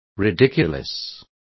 Complete with pronunciation of the translation of ridiculous.